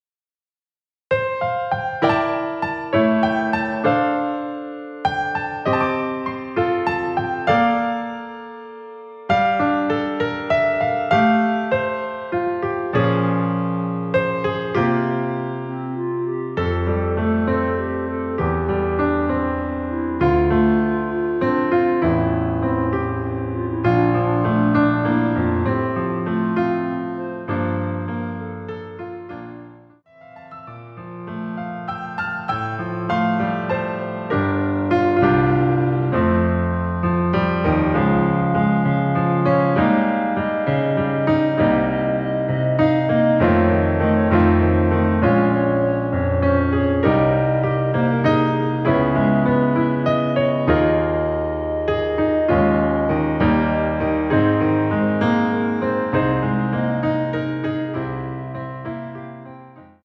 멜로디 포함된 MR 입니다.
멜로디 MR이라고 합니다.
앞부분30초, 뒷부분30초씩 편집해서 올려 드리고 있습니다.
중간에 음이 끈어지고 다시 나오는 이유는